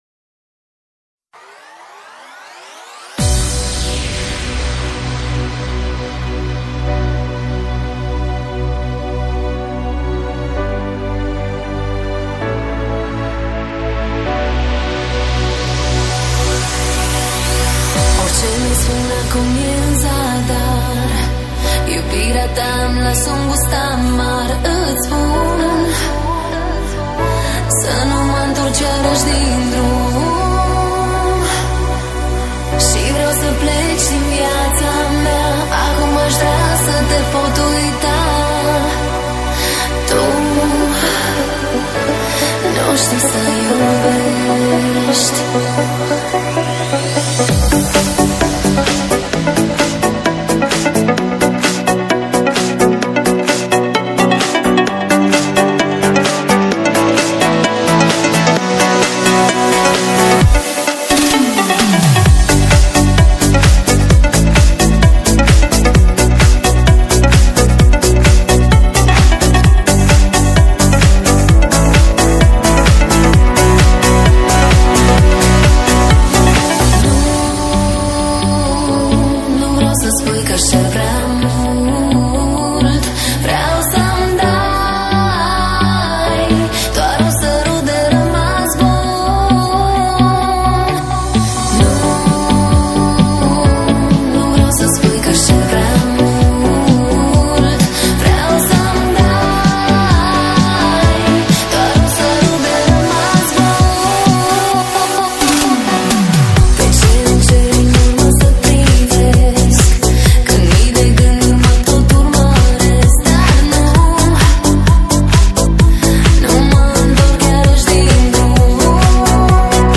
_Восточная__клубная_музыкаMP3_128K
_Vostochnaya__klubnaya_muzykaMP3_128K.mp3